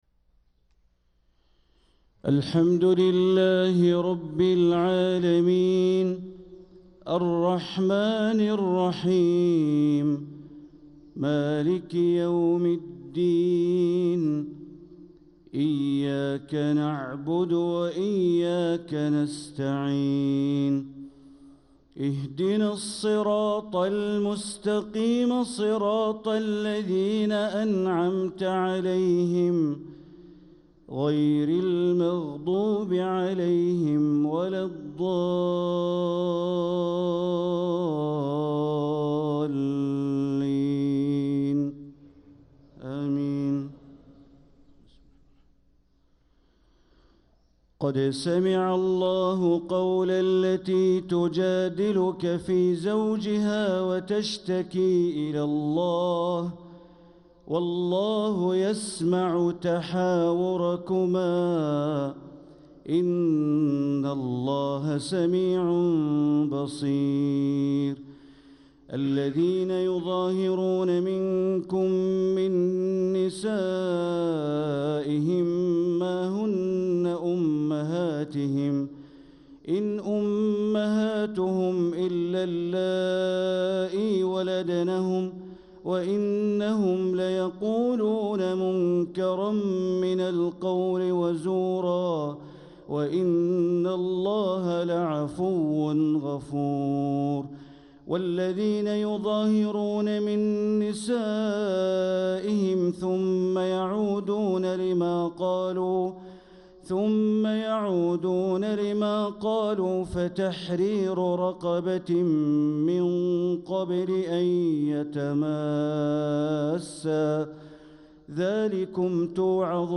صلاة الفجر للقارئ بندر بليلة 14 رجب 1446 هـ
تِلَاوَات الْحَرَمَيْن .